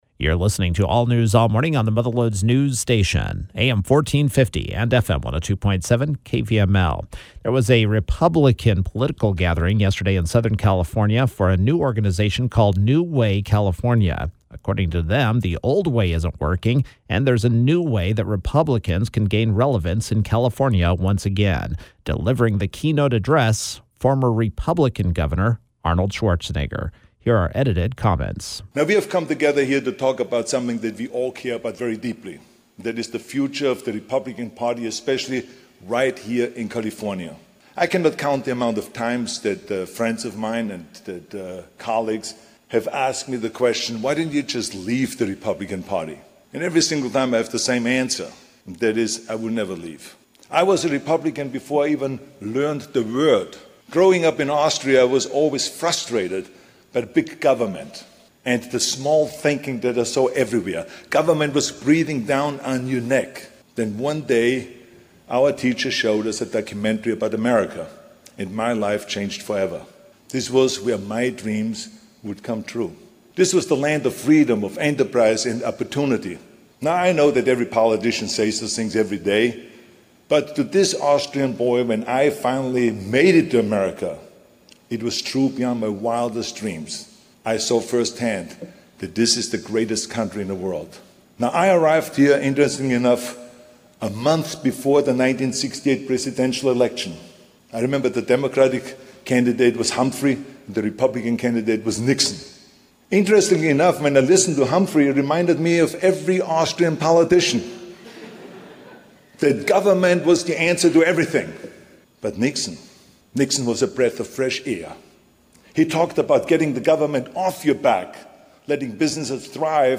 Former Gov. Arnold Schwarzenegger took up that question Wednesday, at an event in Los Angeles organized by a group that hopes to move the state GOP in a new direction in the age of President Donald Trump.